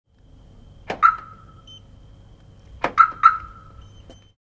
Звук сигнала блокировки и разблокировки авто через брелок